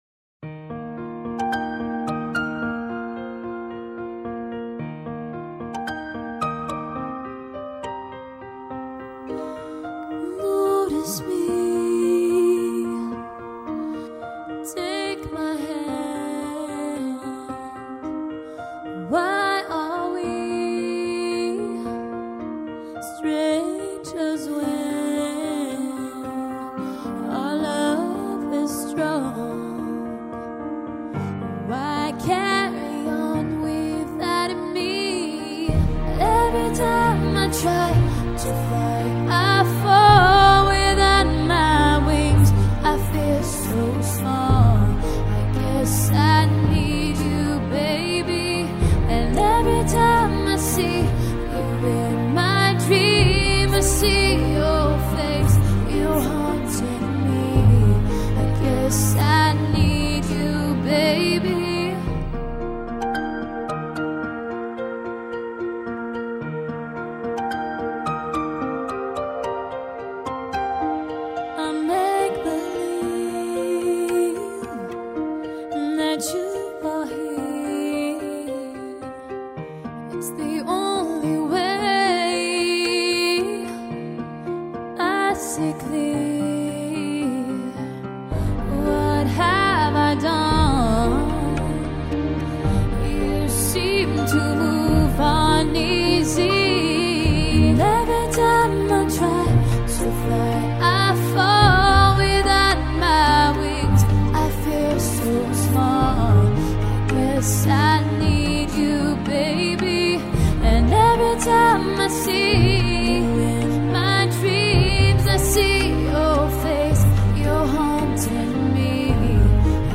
English Karaoke Talented Singers